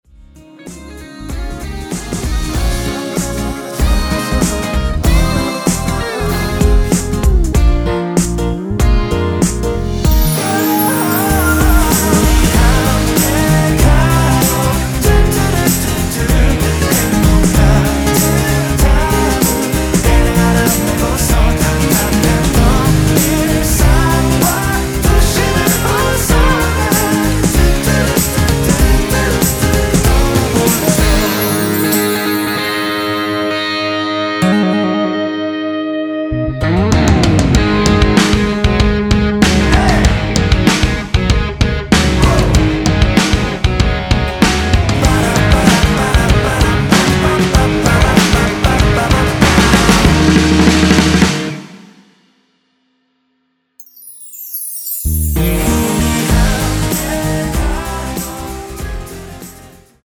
원키 코러스 포함된 MR입니다.(미리듣기 확인)
Ab
앞부분30초, 뒷부분30초씩 편집해서 올려 드리고 있습니다.
중간에 음이 끈어지고 다시 나오는 이유는